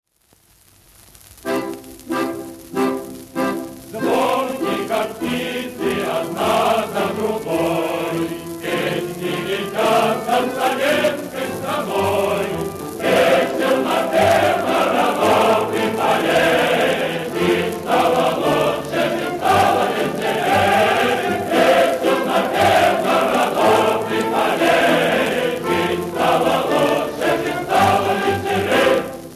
Вот запись первого куплета в этом исполнении: